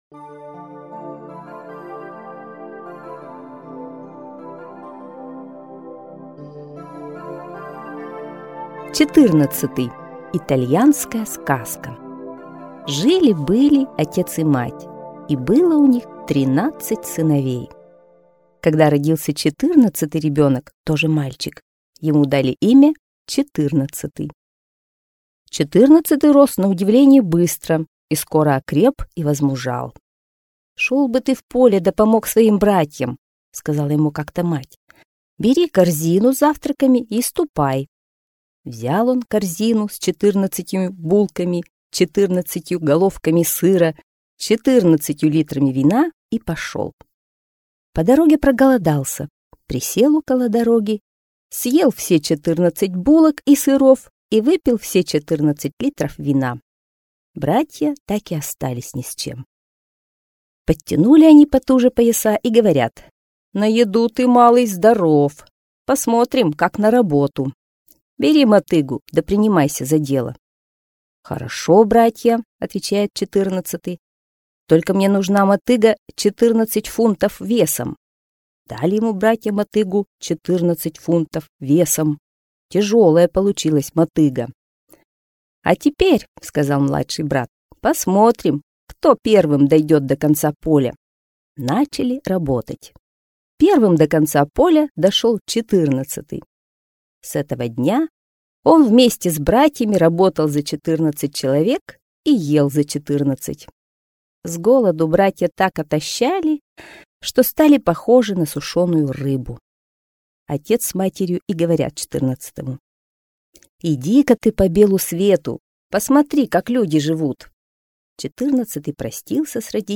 Четырнадцатый - итальянская аудиосказка - слушать онлайн